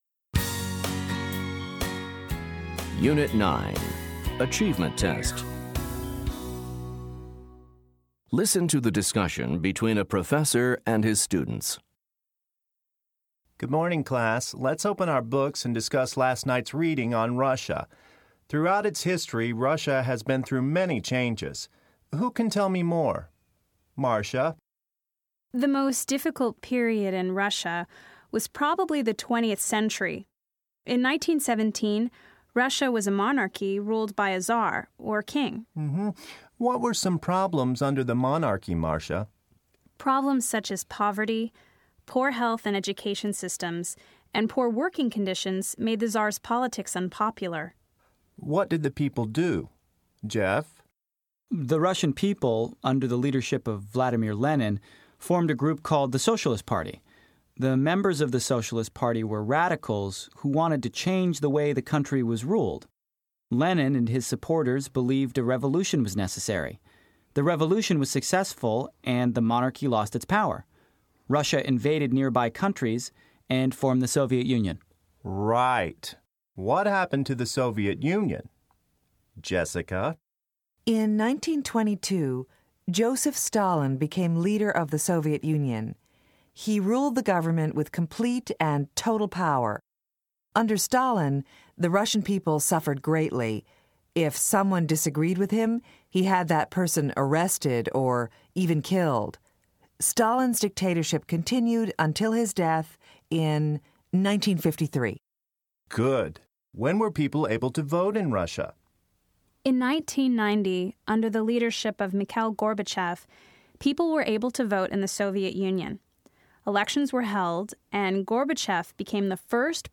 Listen to a discussion between a professor and his students.